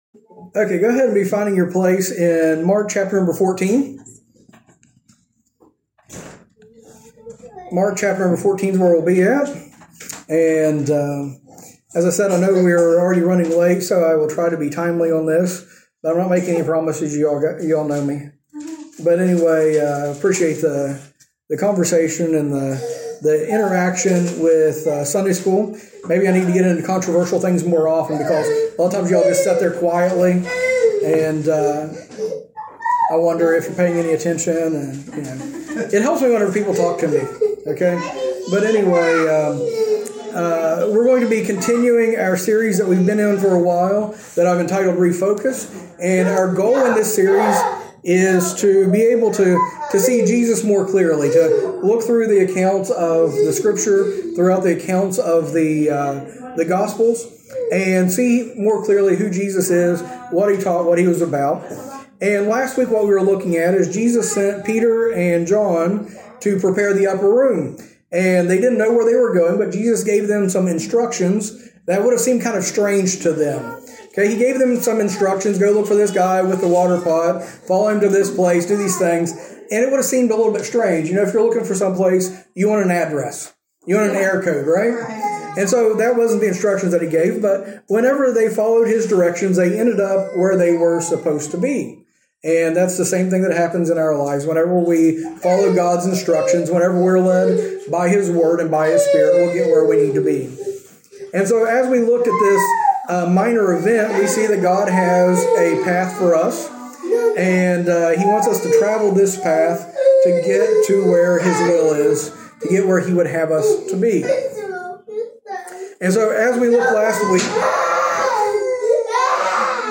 A message from the series "Refocus on Christ."